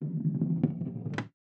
Ball Roll Down Bar.wav